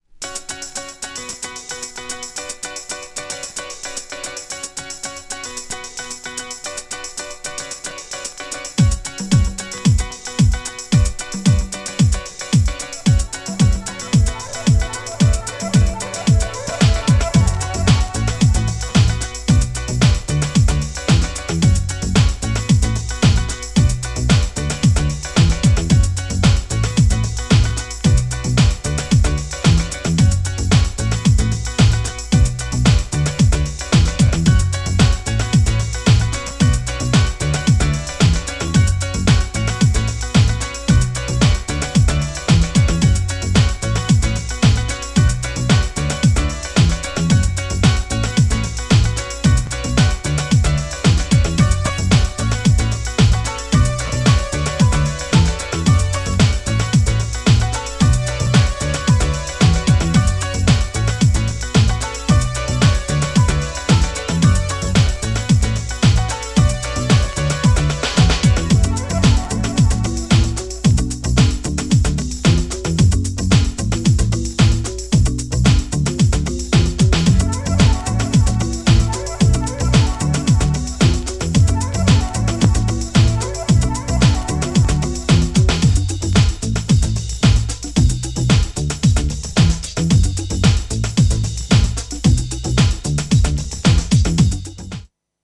カップリング曲もダビーで良い!!
GENRE House
BPM 121〜125BPM